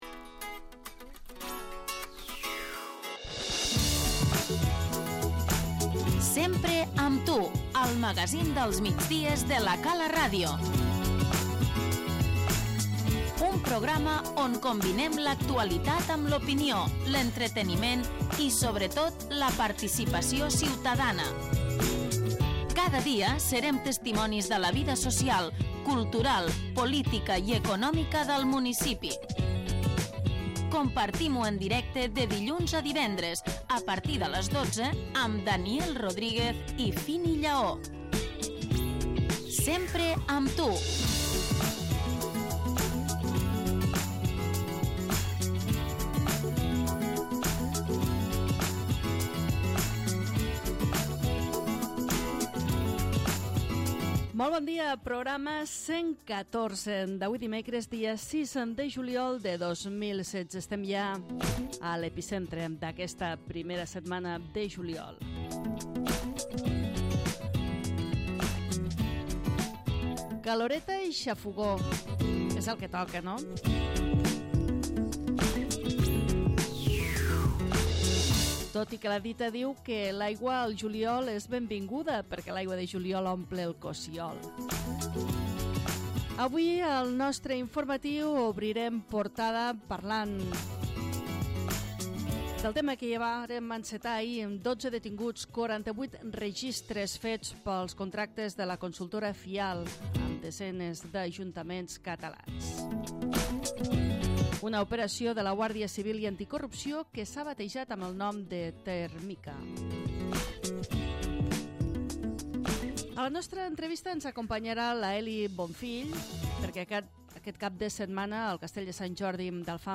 En aquest magazín del migdia de lacalartv corresponent al programa núm. 114 d'avui dimecres dia 6 de juliol de 2016 hem tractat